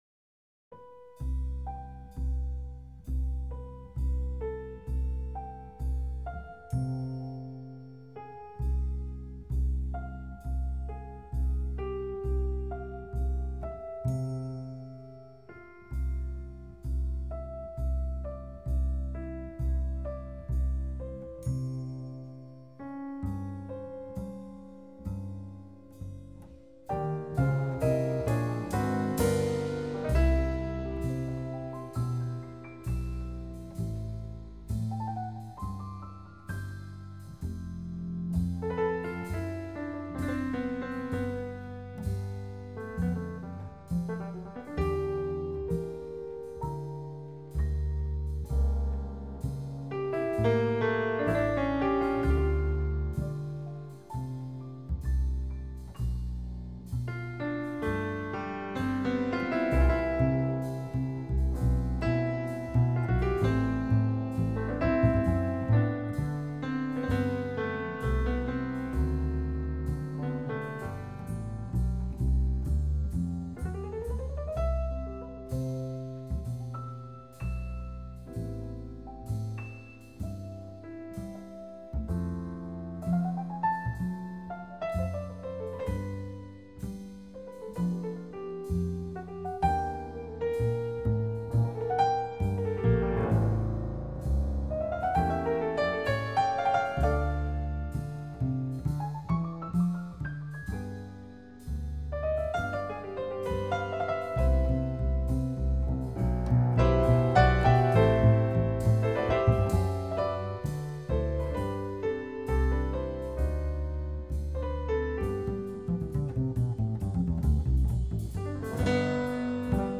爵士三重奏 ． 爵士樂 Swing
鋼琴
貝斯
鼓